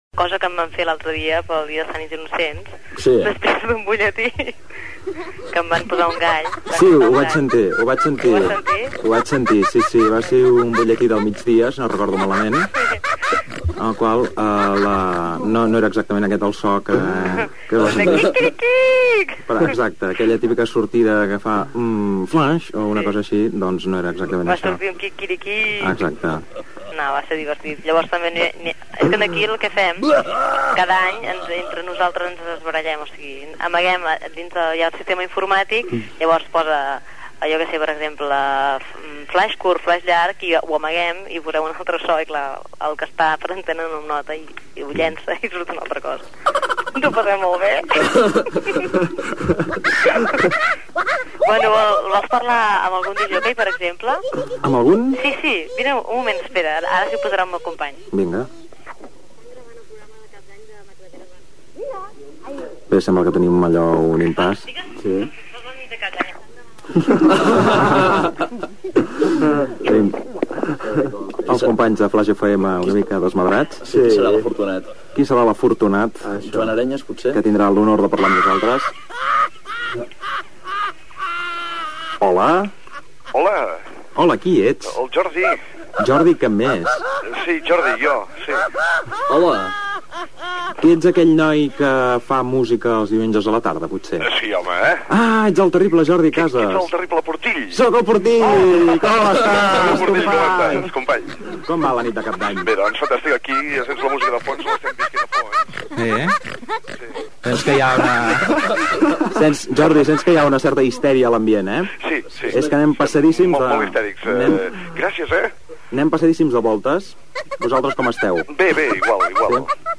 Conversa telefònica entre els col·laboradors del programa i professionals de Flaix FM
Entreteniment